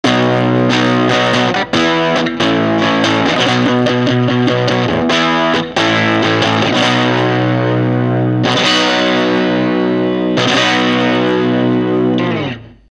高域が強くなりました。